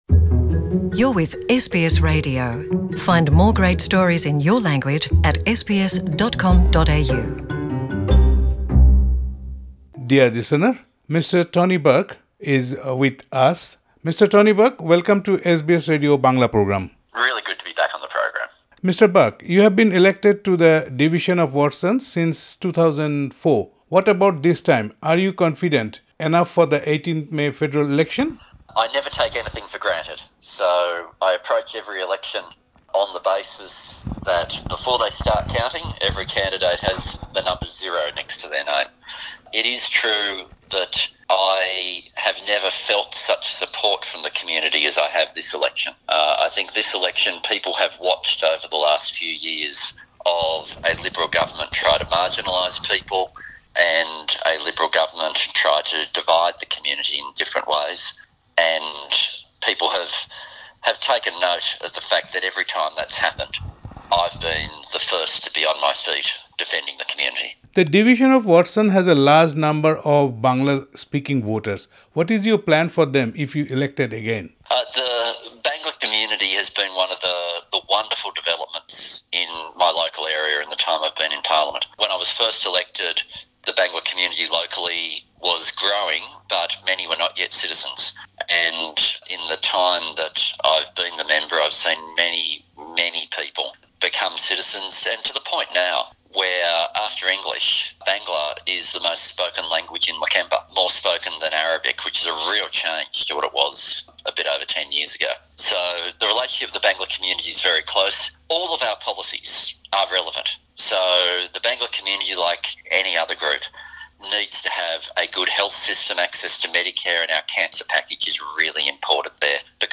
Mr Tony Burke MP has been elected to the division of Watson since 2004. SBS Bangla spoke with him about the 18 May Federal election.
Listen to Mr Tony Burke MP's interview (in English) in the audio player above.